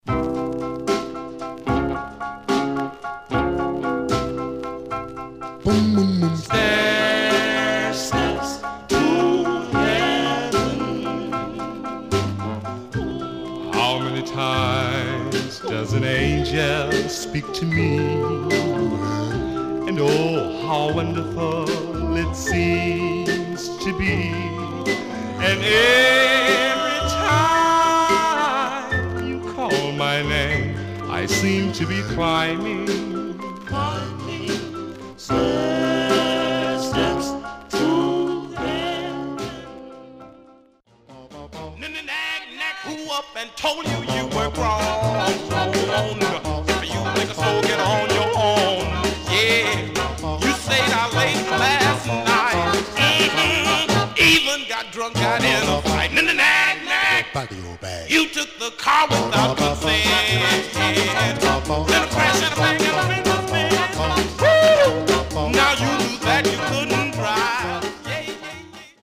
Some surface noise/wear
Mono
Male Black Group Condition